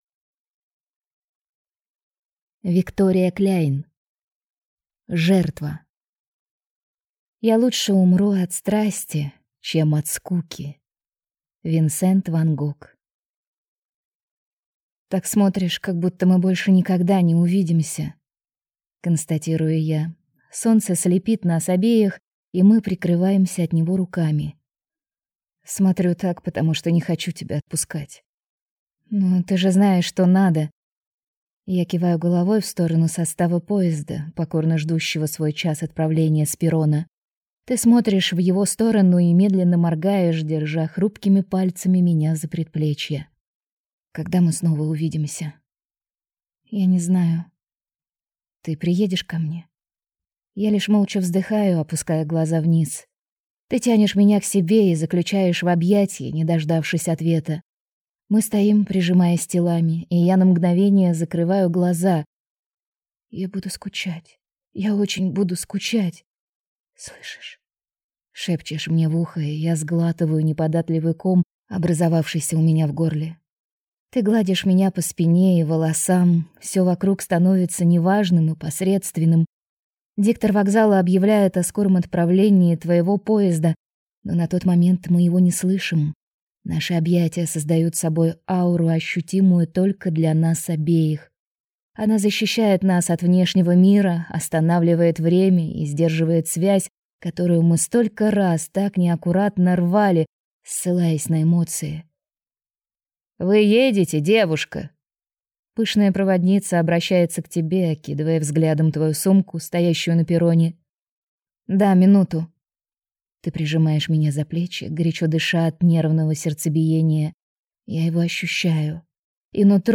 Аудиокнига Жертва | Библиотека аудиокниг